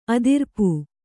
♪ adirpu